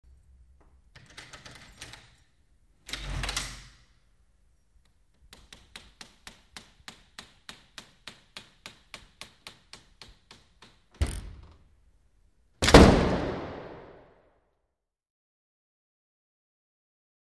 Heavy Wood Door Open With Keys And Slow Creaks Heavy Close In Reverberant Hall